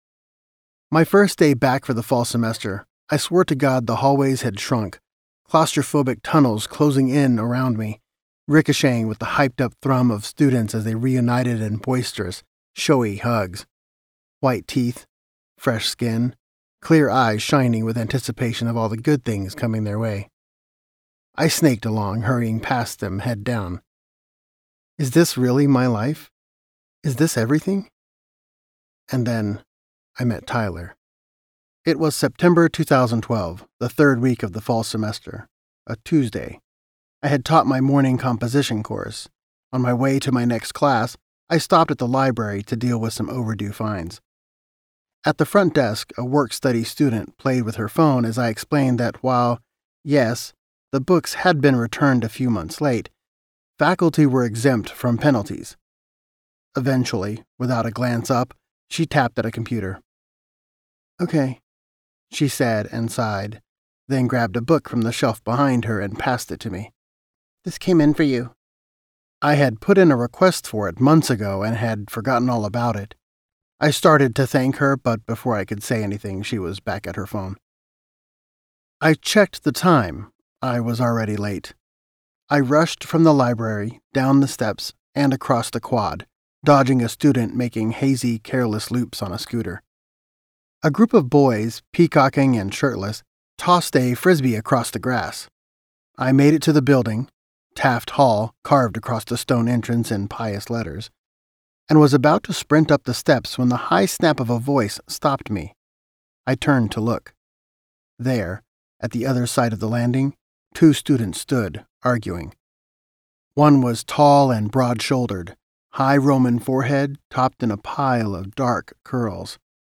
Providence - Vibrance Press Audiobooks - Vibrance Press Audiobooks